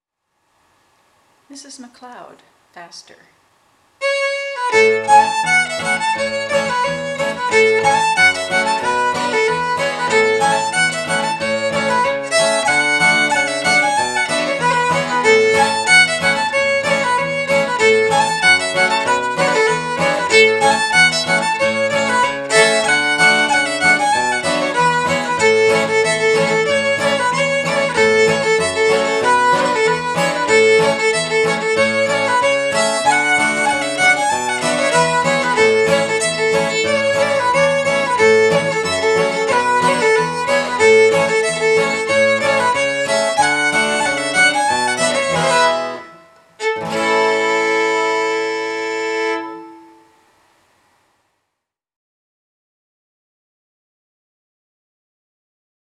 guitar accompaniment
Mrs MacLeod, Faster
MrsMacLeodFasterA.aif